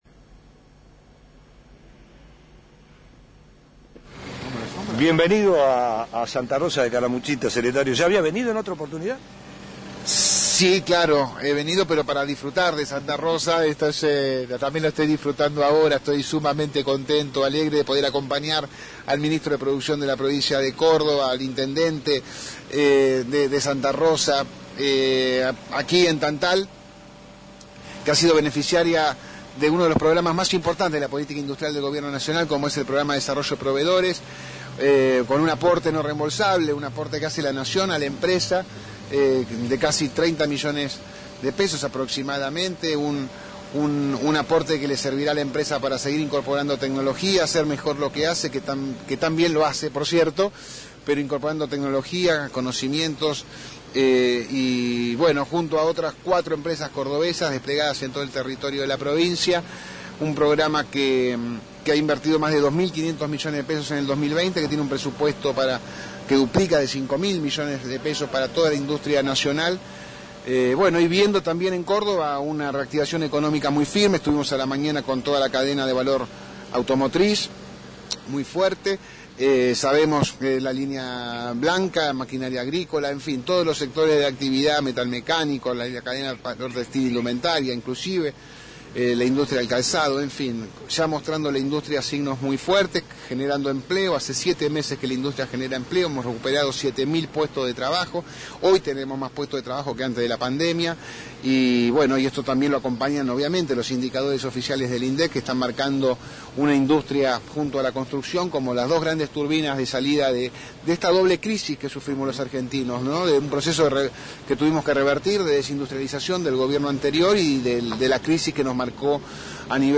En diálogo con Flash FM el secretario de Desarrollo Productivo de la Nación Ariel Schale anunció la llegada de fondos no reintegrables para la empresa Tantal de santa Rosa de Calamuchita y para el Polo Tecnológico en Villa General Belgrano.